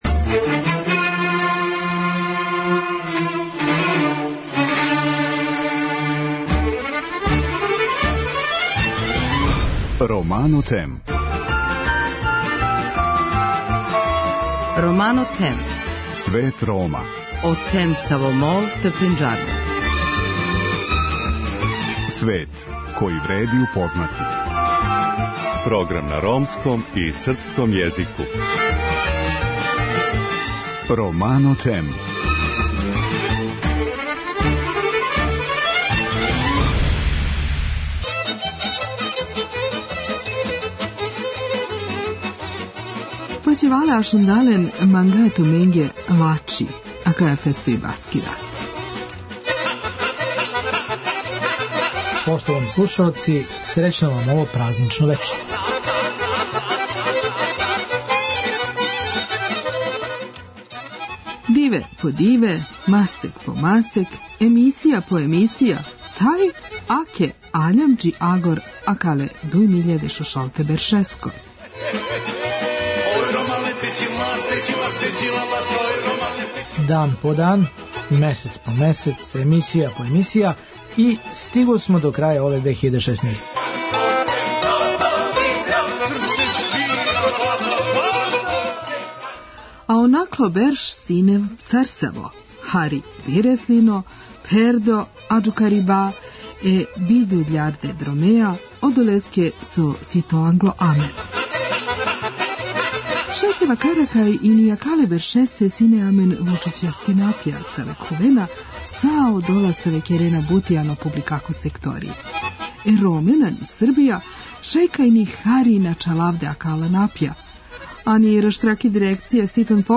Вечерашње агенцијске вести на ромском језику биће заправо наше новогодишње жеље упућене оним припадницима ромске заједнице који одлазећу 2016. неће памтити по најбољем.